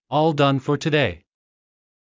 ｻﾞｯﾂ ｵｰﾙ ﾌｫｰ ﾄｩﾃﾞｨ